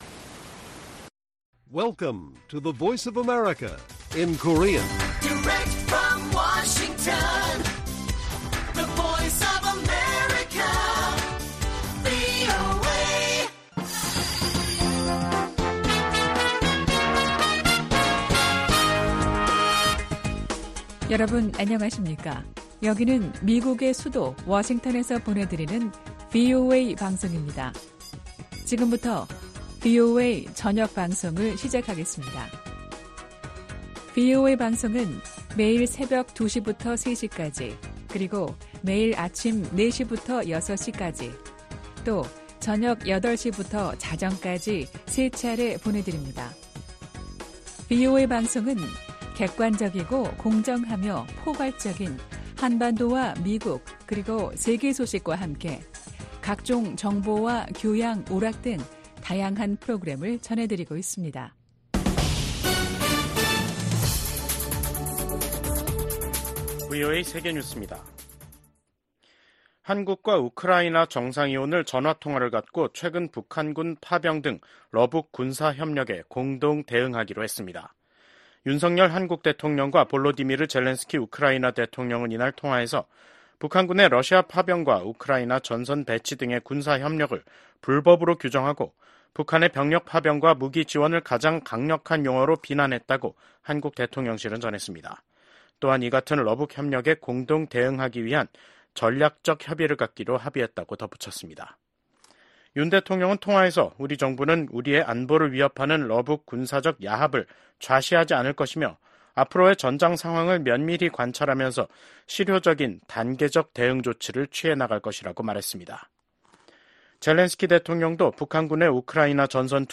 VOA 한국어 간판 뉴스 프로그램 '뉴스 투데이', 2024년 10월 29일 1부 방송입니다. 한국 정보 당국은 러시아에 파병된 북한군 중 고위급 장성을 포함한일부 병력이 전선으로 이동했을 가능성이 있다고 밝혔습니다. 미 국방부가 북한군 1만명이 러시아의 우크라이나 전쟁을 지원하기 위해 파병됐다는 사실을 확인했습니다.